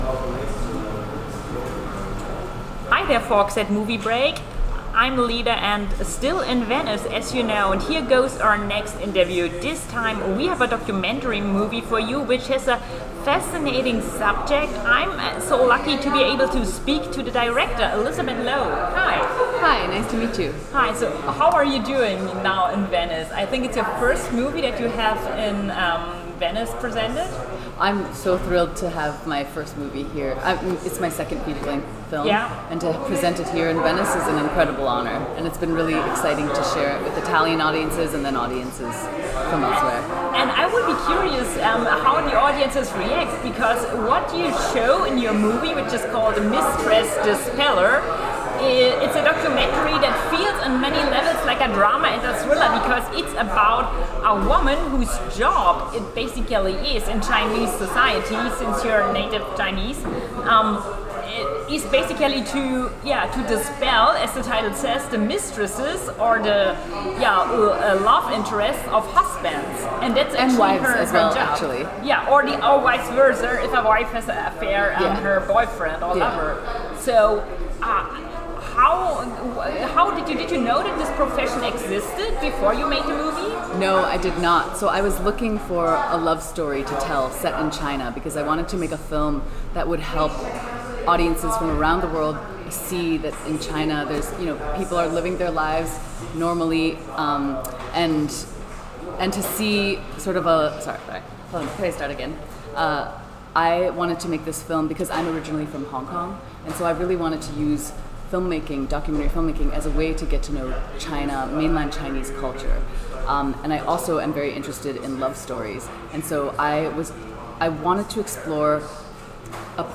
Die Filmfestspiele von Venedig 2024 - Interview